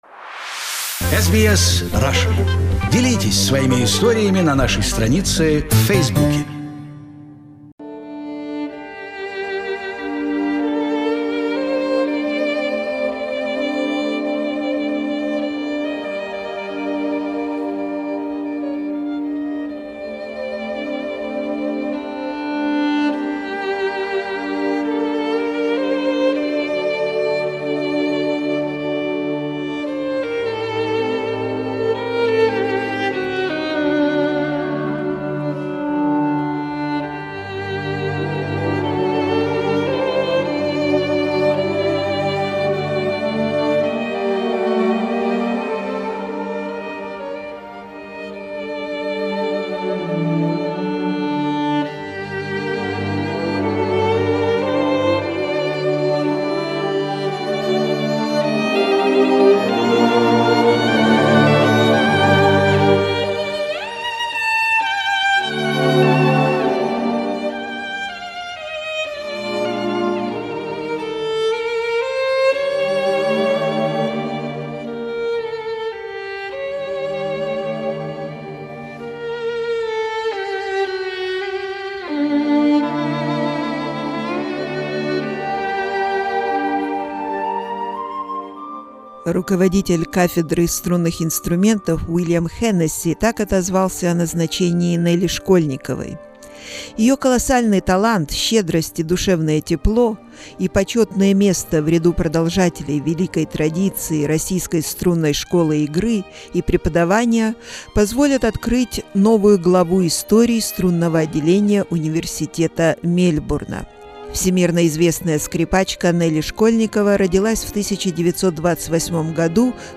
We spoke with her in July 2008 and recorded a program dedicated to her 80th Birthday.